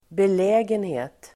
Uttal: [bel'ä:genhe:t]